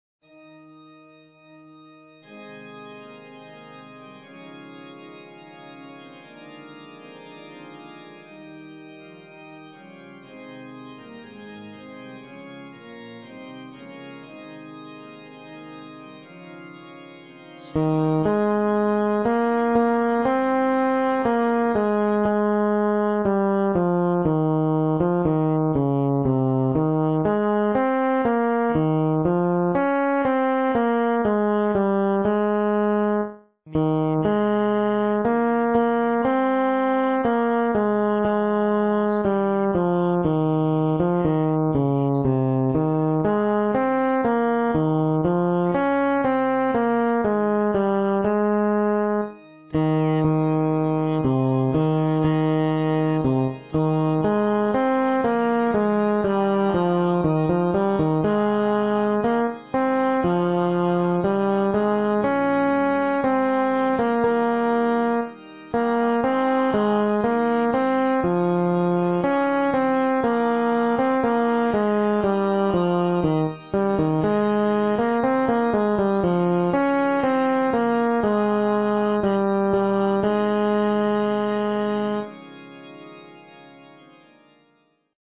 ソロ：テノール（歌詞付き）